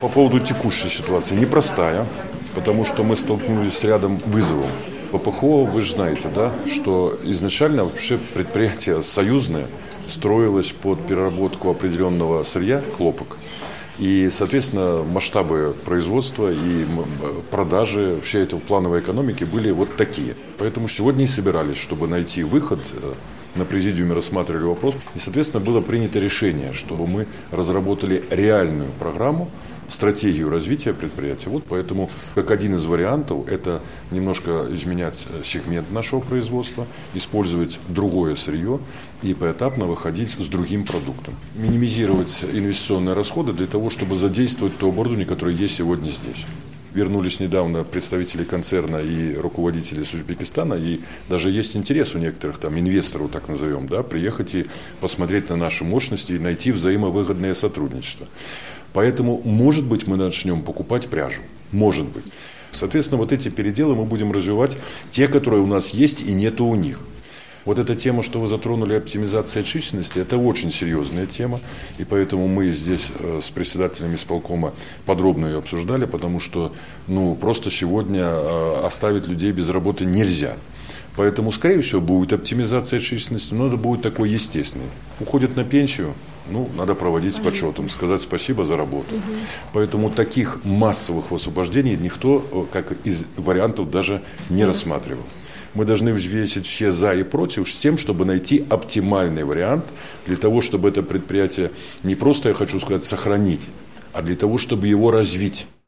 Вице-премьер Юрий Назаров в интервью журналистам отметил, что сейчас основное сырье выросло в цене наполовину.